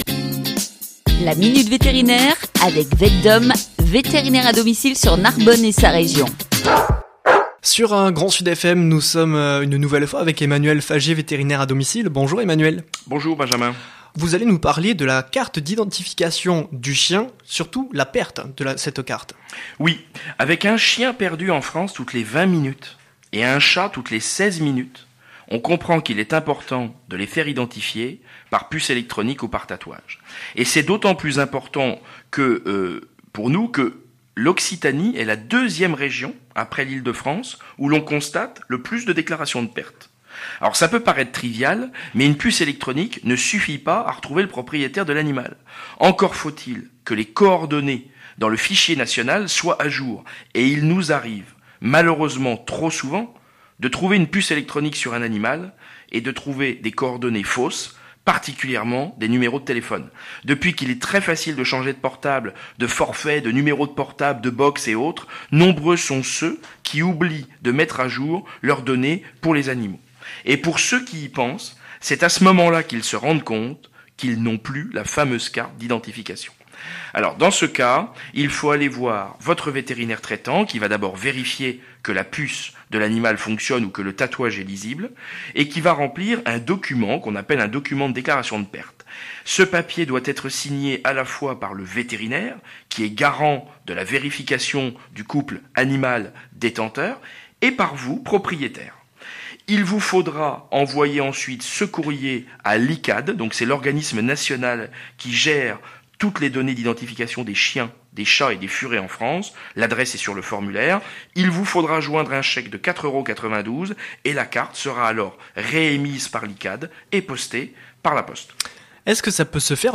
La Minute vétérinaire sur GrandSud FM avec VETDOM